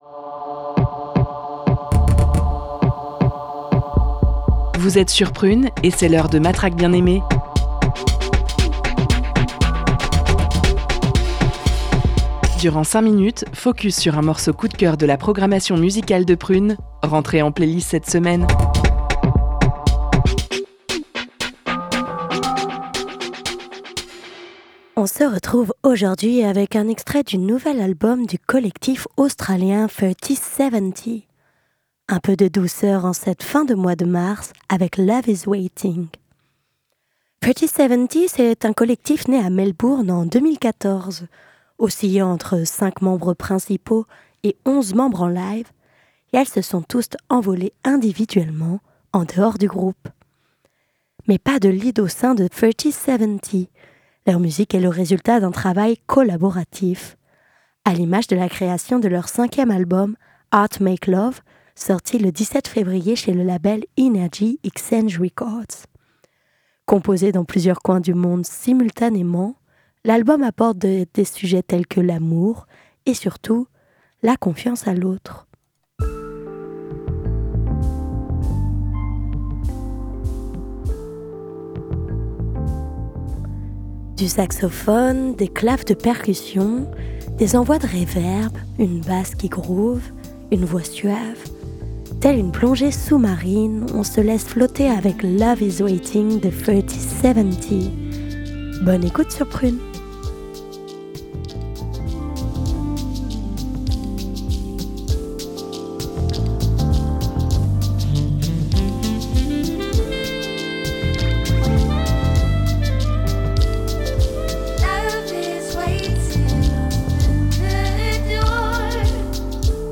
De la douceur